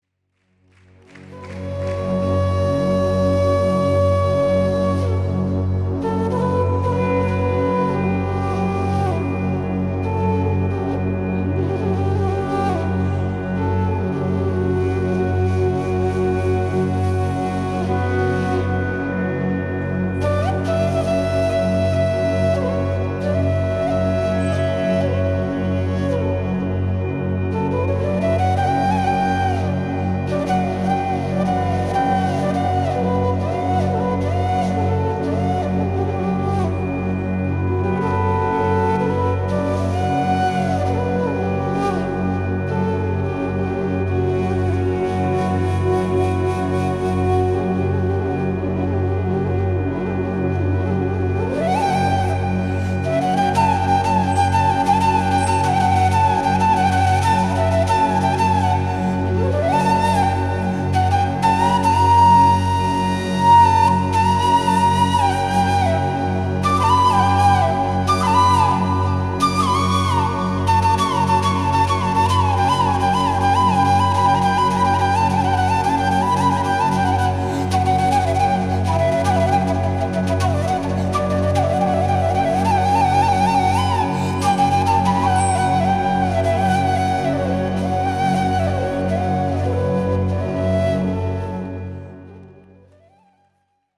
Style: Folk